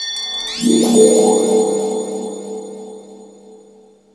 THE SPLASH.wav